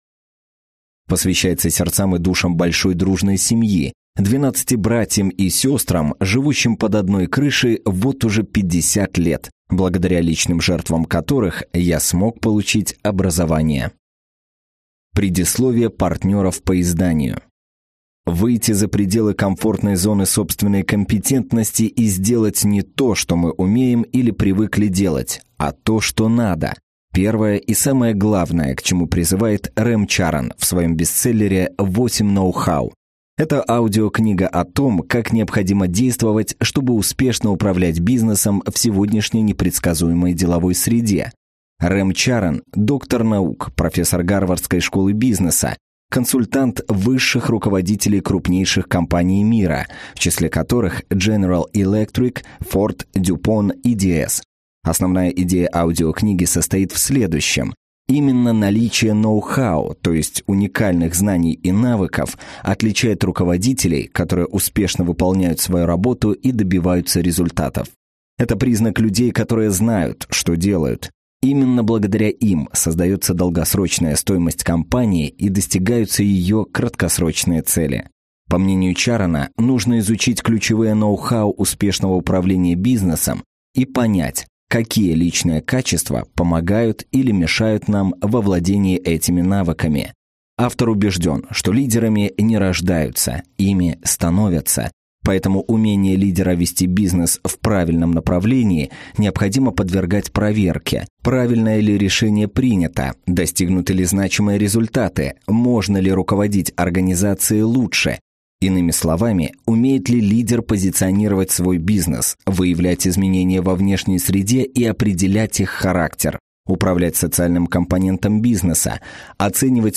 Аудиокнига Ноу-хау. 8 навыков, которыми вам необходимо обладать, чтобы добиваться результатов в бизнесе | Библиотека аудиокниг